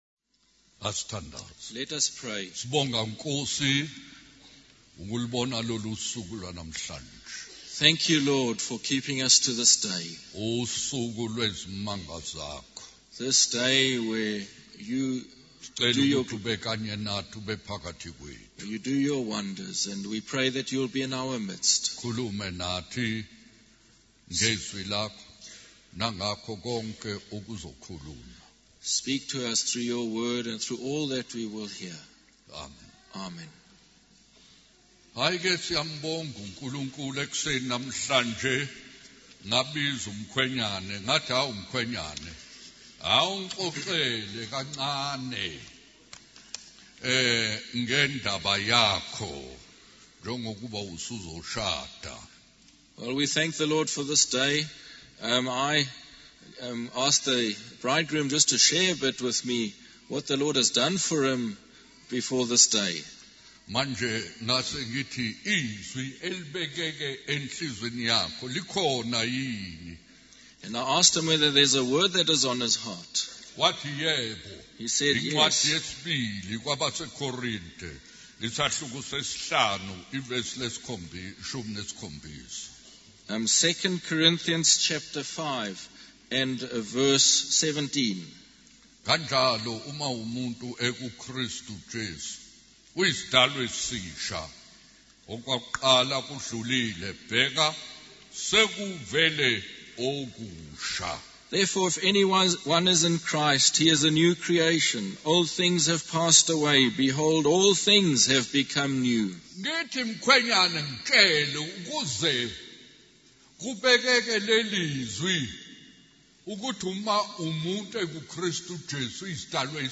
In this sermon, the preacher begins by praying for God's blessing on the congregation. He then shares the testimony of a man who had a life-changing encounter with Jesus.